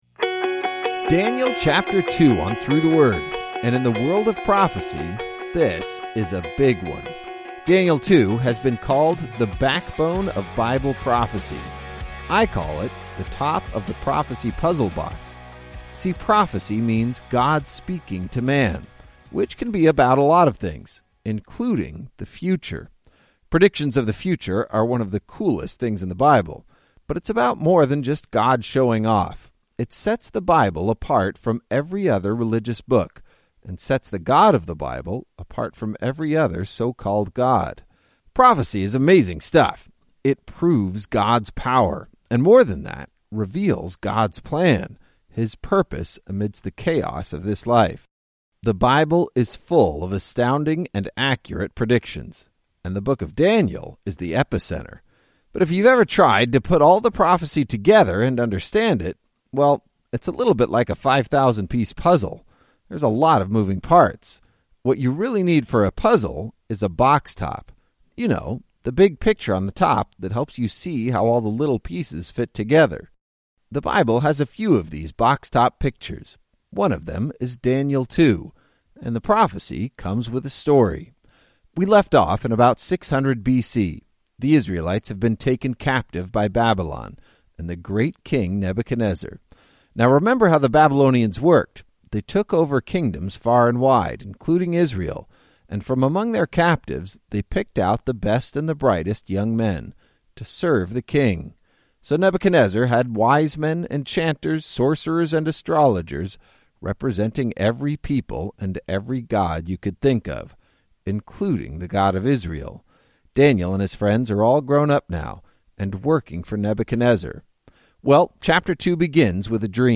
19 Journeys is a daily audio guide to the entire Bible, one chapter at a time.